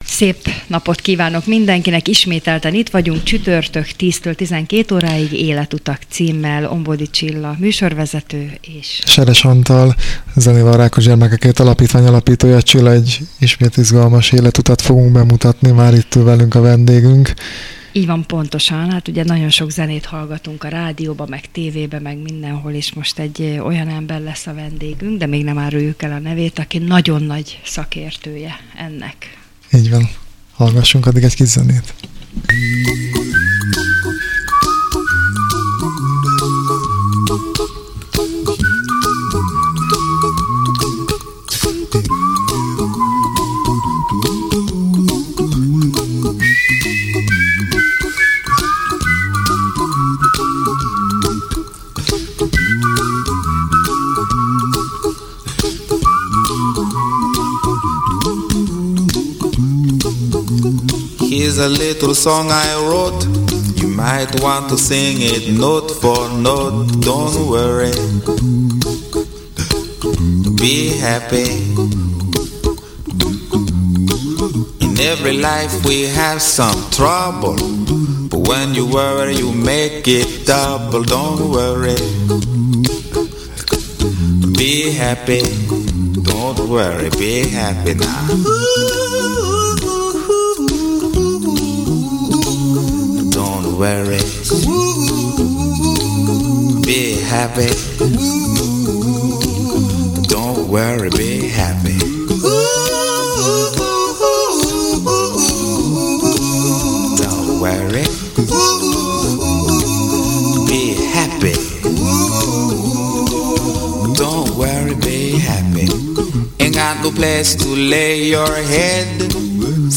Rádió Bézs beszélgetés – Életutak – 1. rész, 2. rész